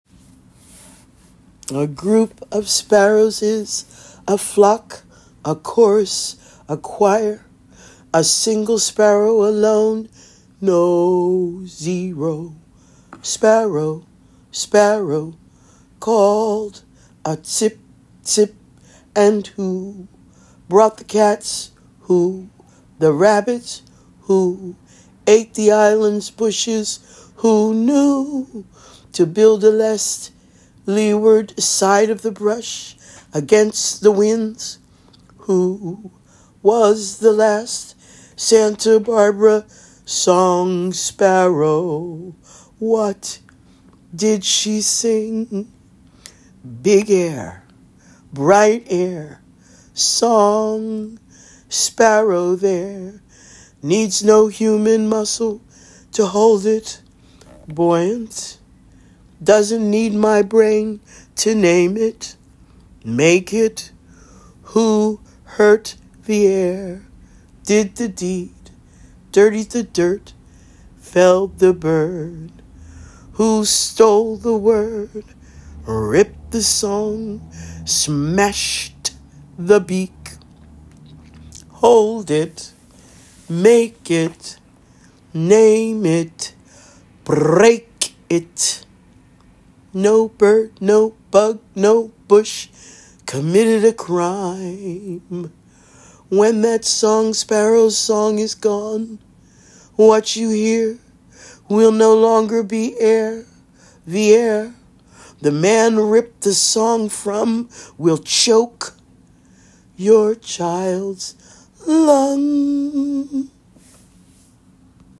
and I asked her to do a simple recording on her phone. (Not all the parts she sings are in the text above.)